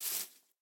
snapshot / assets / minecraft / sounds / step / grass2.ogg
grass2.ogg